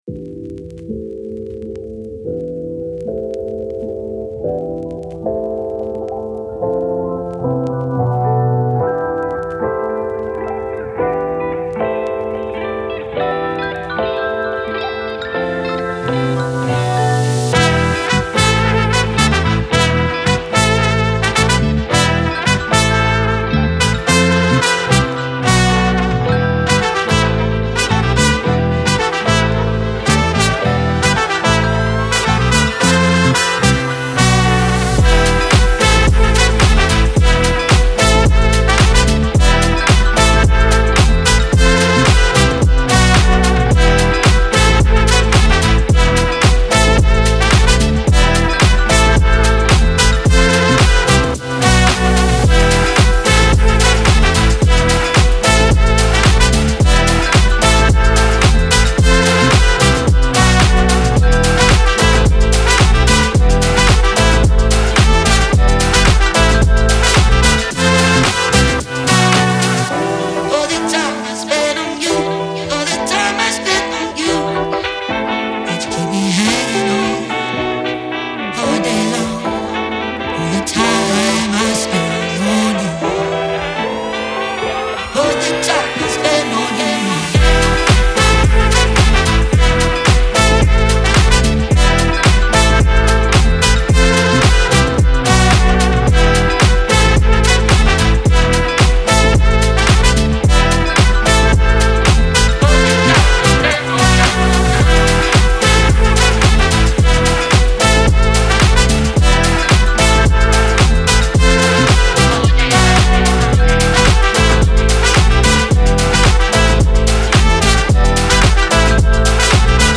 پر‌انرژی
هاوس